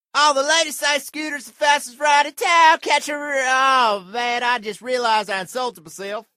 scooters the fastest sound button getting viral on social media and the internet Here is the free Sound effect for scooters the fastest that you can download
scooter.mp3